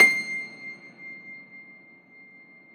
53e-pno22-C5.wav